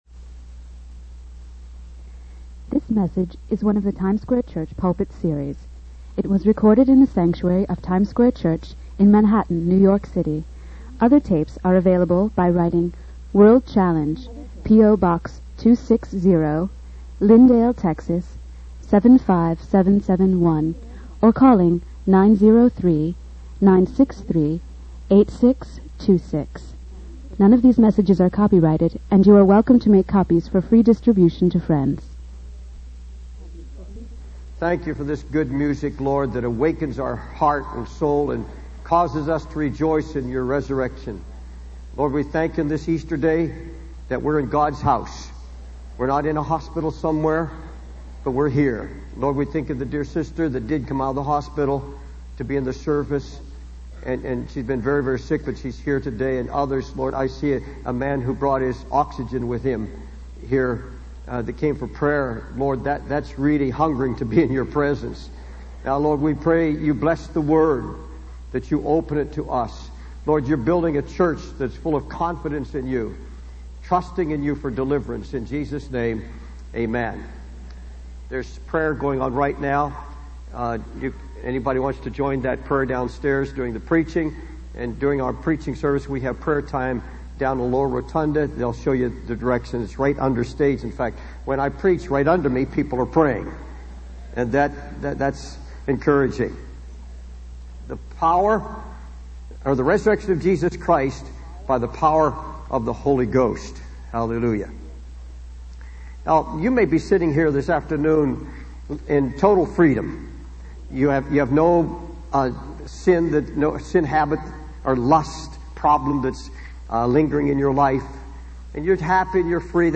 In this sermon, the preacher emphasizes the importance of understanding the New Covenant and the freedom it brings.
It was recorded in the sanctuary of Times Square Church in Manhattan, New York City.